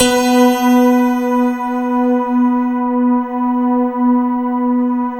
SYN_Piano-Pad2.wav